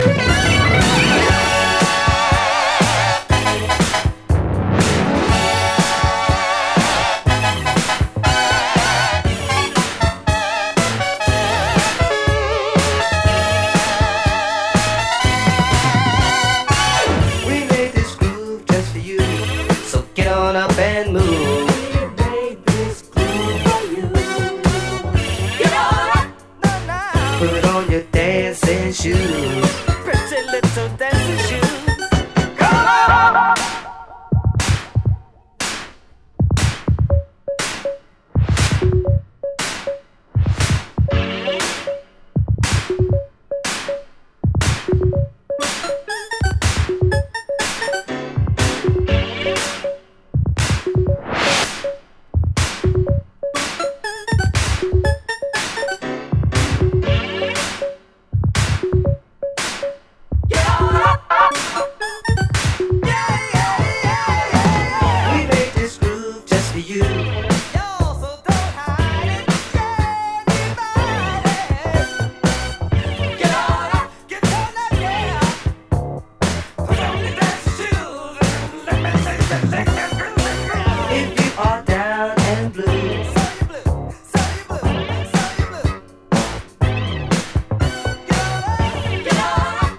forgotten disco and boogie
Disco House Sale